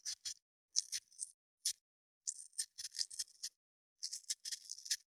497,桂むき,大根の桂むきの音切る,包丁,厨房,台所,野菜切る,咀嚼音,ナイフ,調理音,
効果音厨房/台所/レストラン/kitchen食材